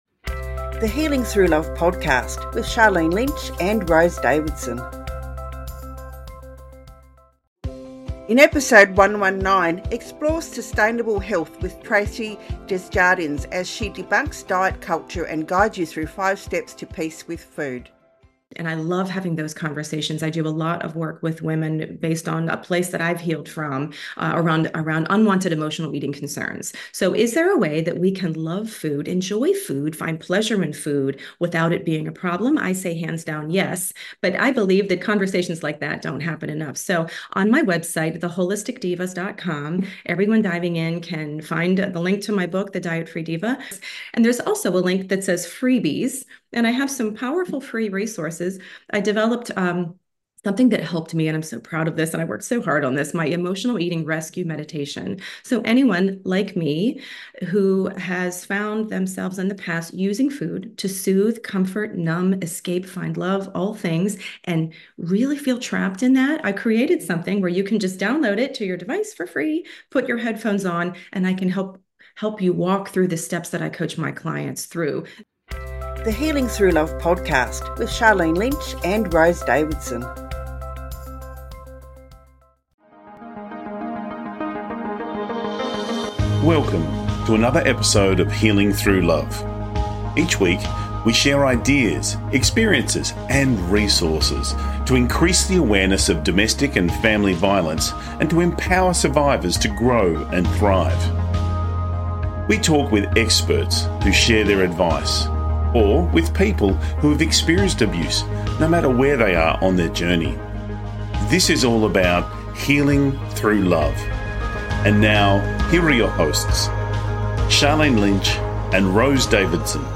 In this interview, she outlines her transformative 5-step plan to attain freedom, peace, and a balanced relationship with food, body, and self.